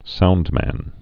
(soundmăn)